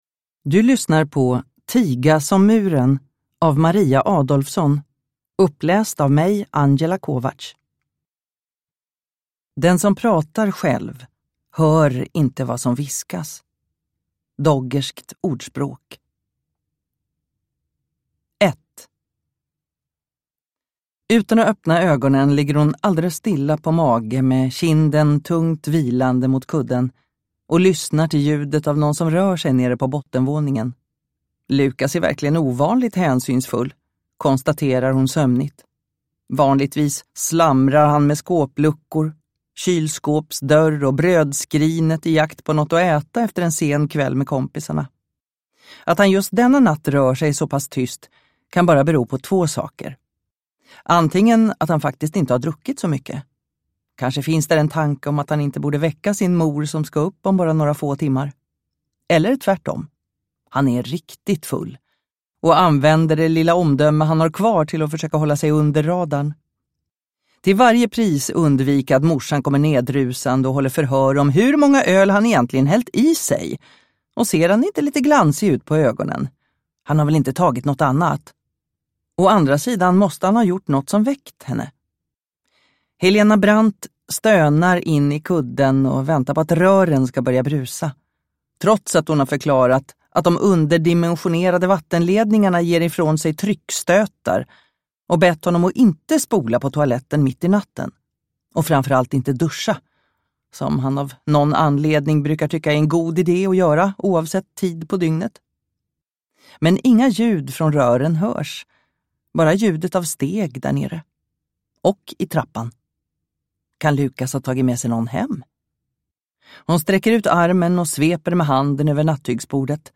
Tiga som muren – Ljudbok
Deckare & spänning Njut av en bra bok